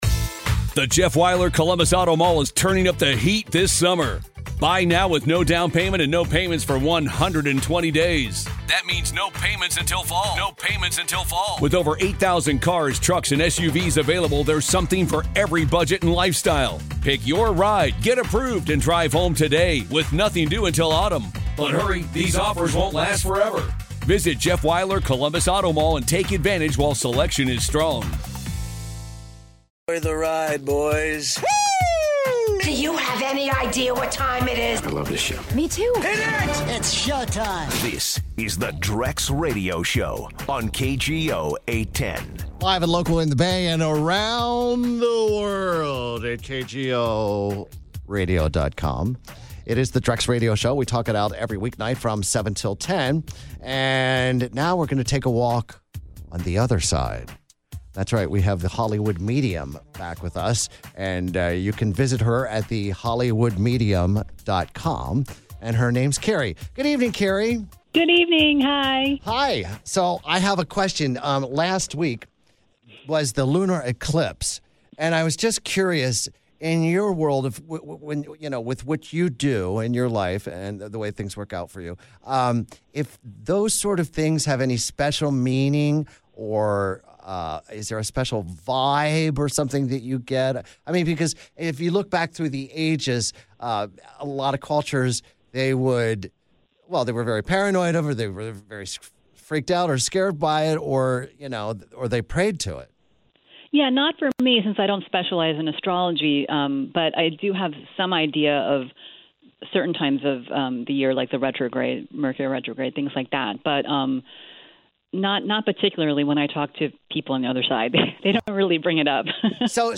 Listeners check in with "The Hollywood Medium."